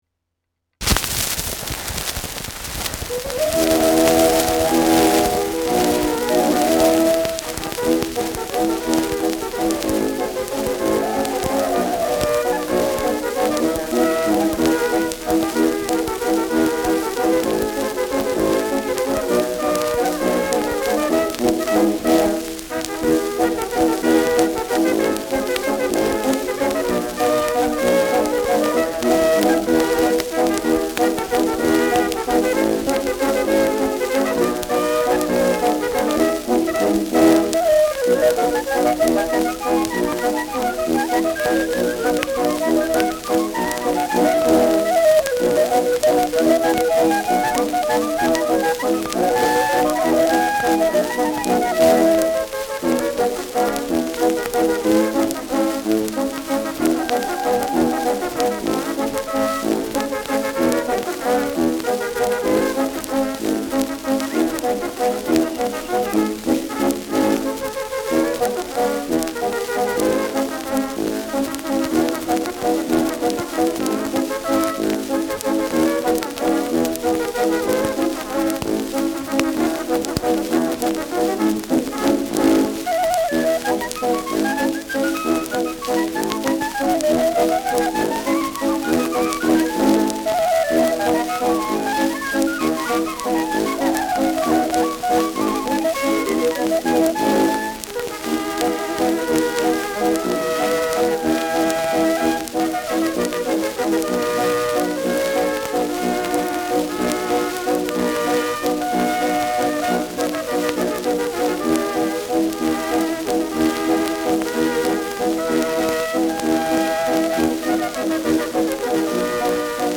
Schellackplatte
ausgeprägtes Rauschen : Knistern
Dachauer Bauernkapelle (Interpretation)